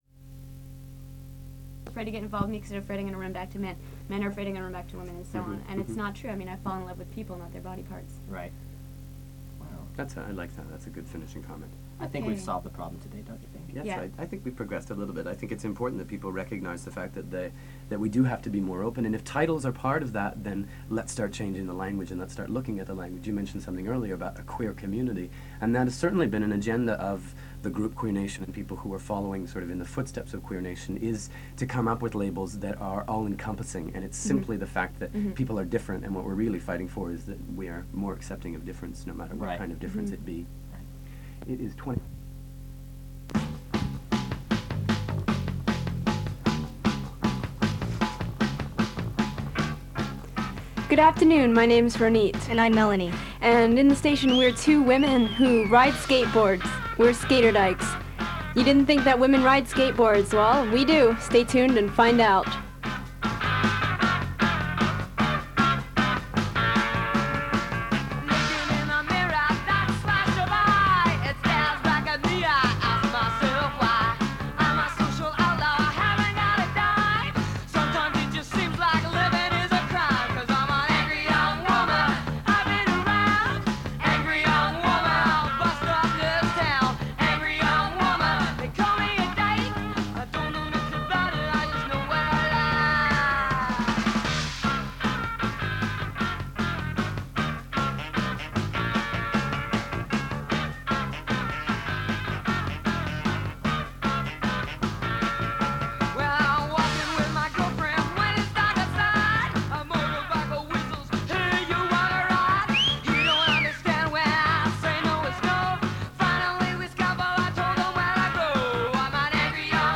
They discuss bisexuality, SkaterDykes, and lesbian motherhood. A recording from the protests following the 1990 Sex Garage police raids plays.
Gay Day was an annual 24 hour broadcast event held by CKUT Radio (which hosted the Dykes on Mykes broadcast), from 1989 to 1991.